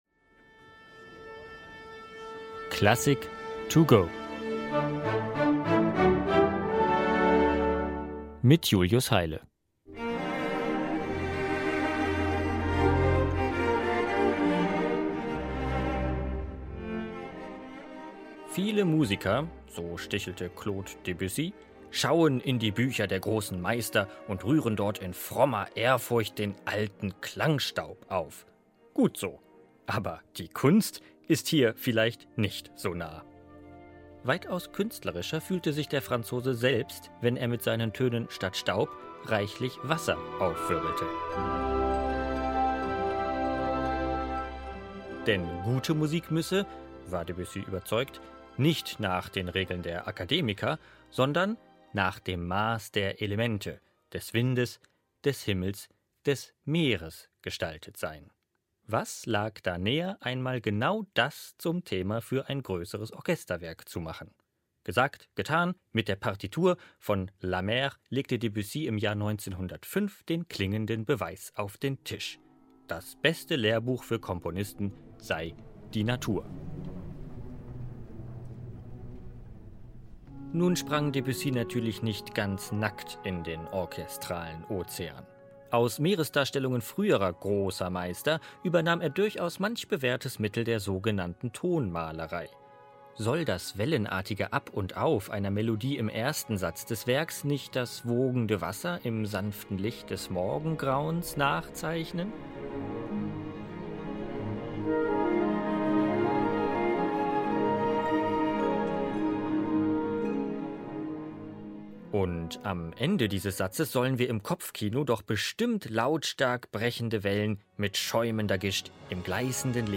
Erfahren Sie mehr in dieser kurzen Werkeinführung von